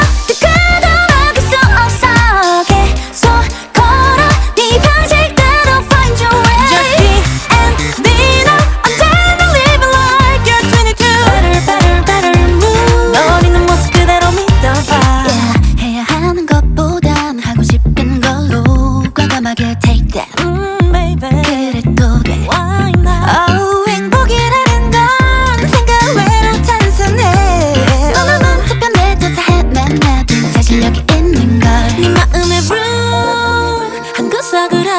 Жанр: K-pop / Поп / Русские